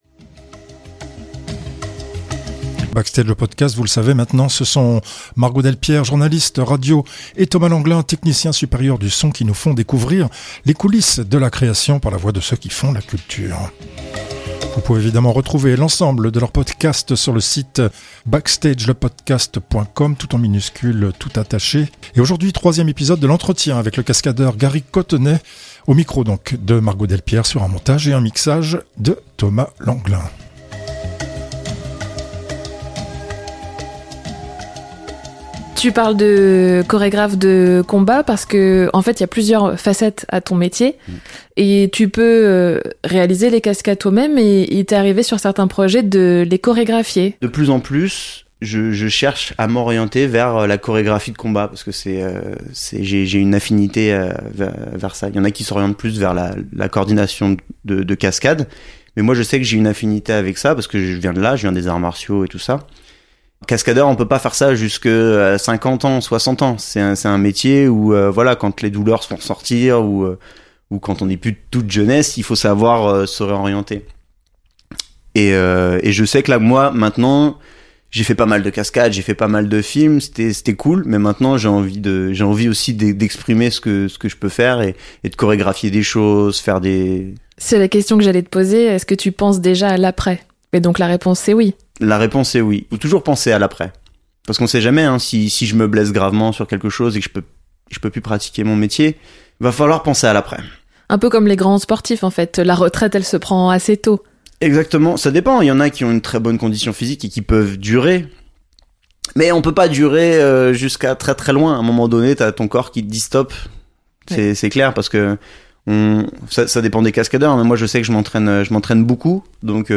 Aujourd’hui troisième épisode de l’entretien